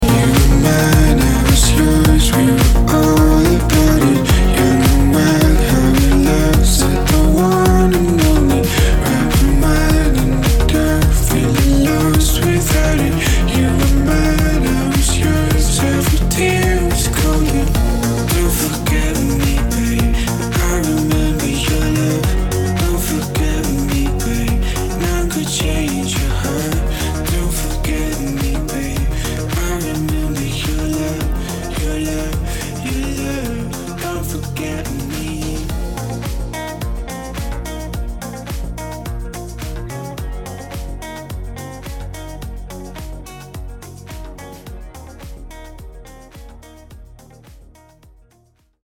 • Качество: 192, Stereo
гитара
мужской вокал
deep house
dance
спокойные